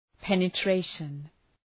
{,penə’treıʃən}